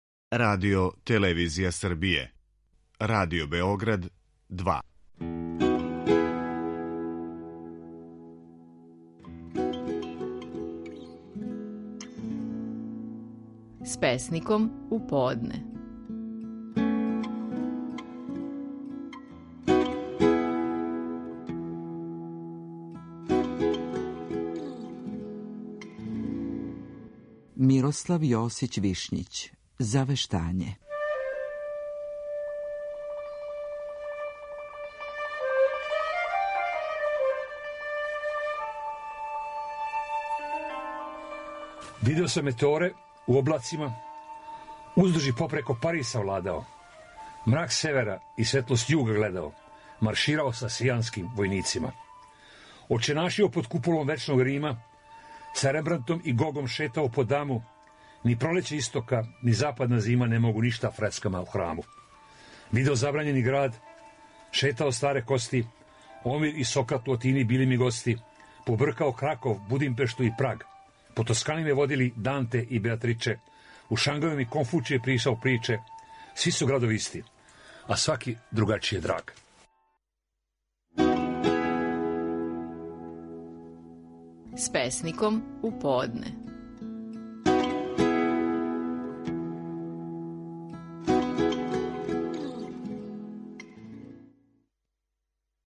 Стихови наших најпознатијих песника, у интерпретацији аутора.
Мирослав Јосић Вишњић интерпретира „Завештање".